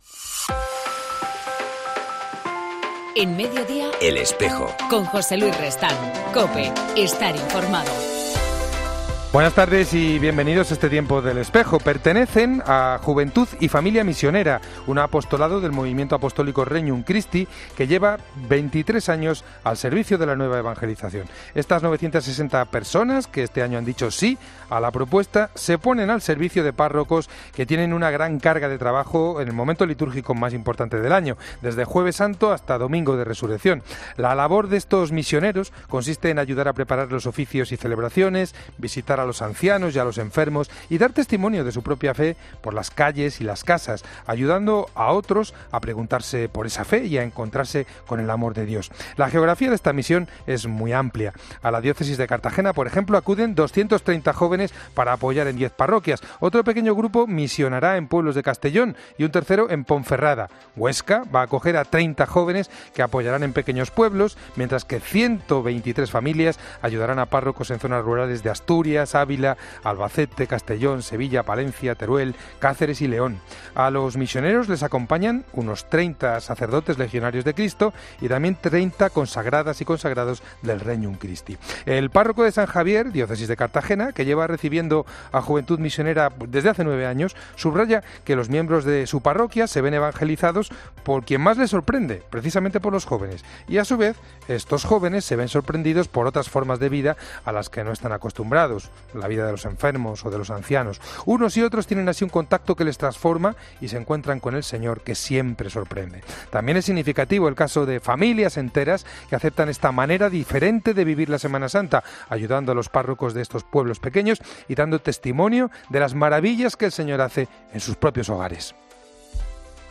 entrevistamos al religioso franciscano